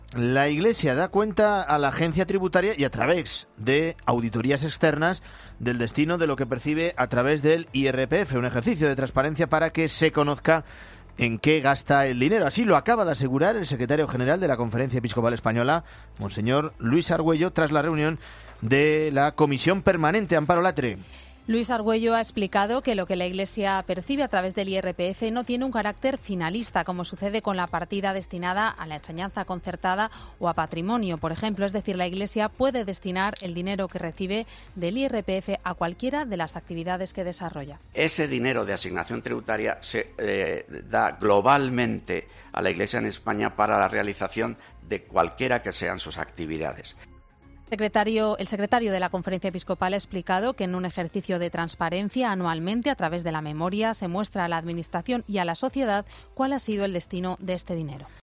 Mons. Luis Argüello ha explicado en rueda de prensa cómo la Iglesia ofrece públicamente sus cuentas cada año